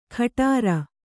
♪ khaṭāra